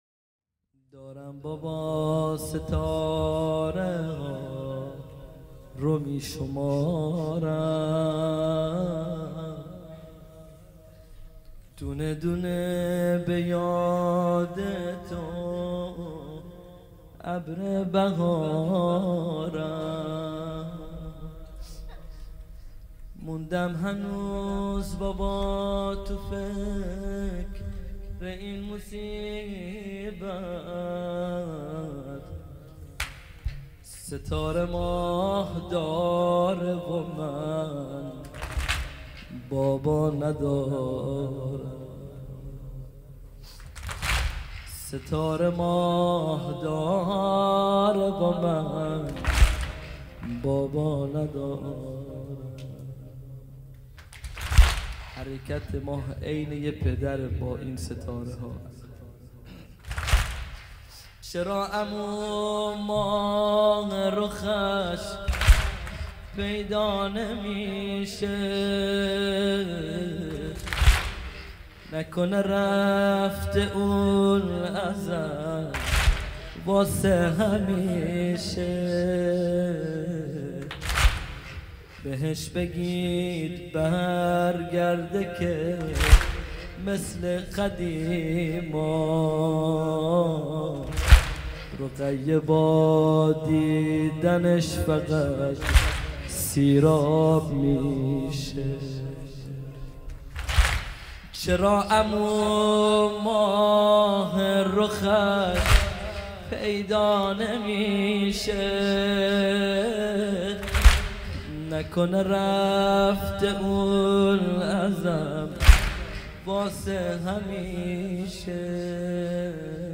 مداحی
شب 3 محرم- سال 1439 هجری قمری | هیأت علی اکبر بحرین